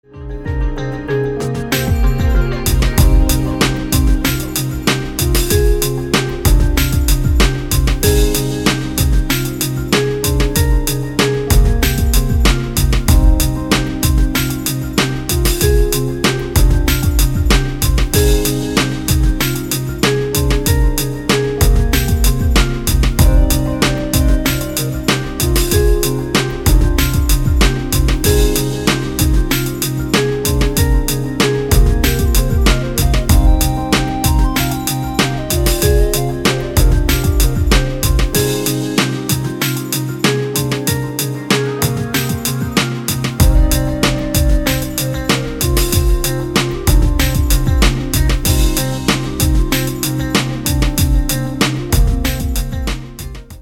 • Качество: 256, Stereo
красивые
спокойные
без слов